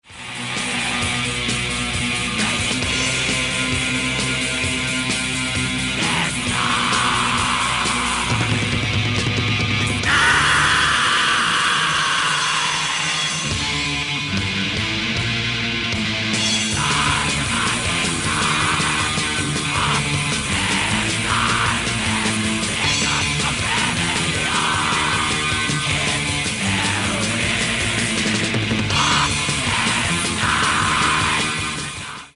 from simple elements built black metal in the flowing style
demo, 1995